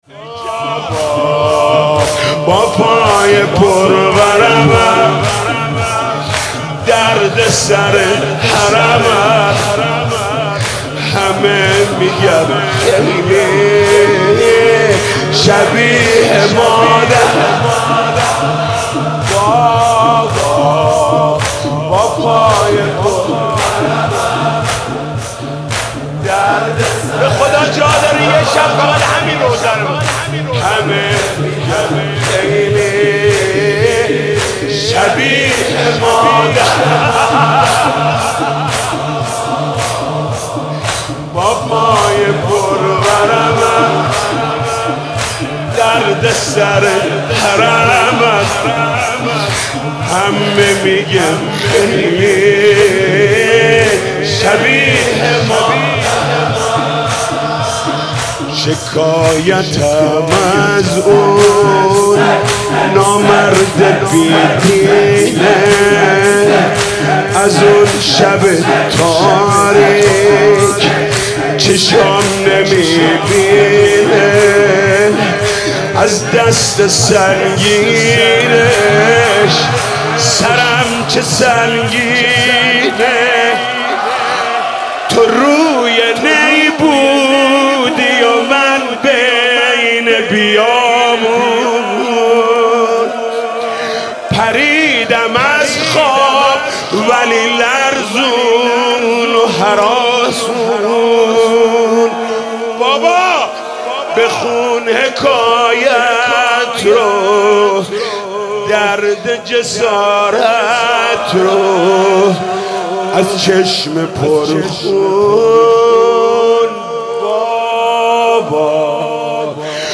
«فاطمیه 1396» زمینه: بابا با پای بر ورم دردسر حرمم